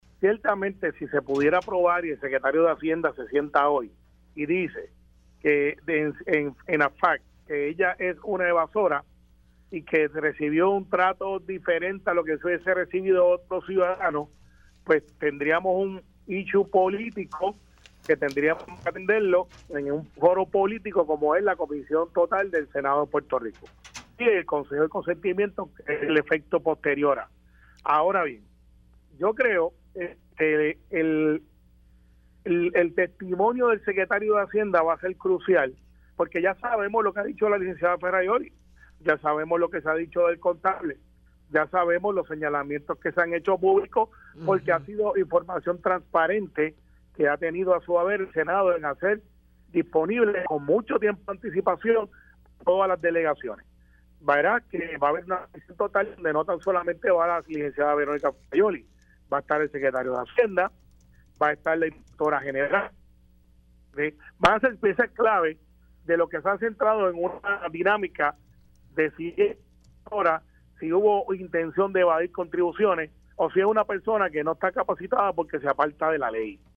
312-CARMELO-RIOS-SENADOR-PNP-CRUCIAL-TESTIMONIO-DE-SECRETARIO-DE-HACIENDA-SOBRE-VERONICA-FERRAIOULI.mp3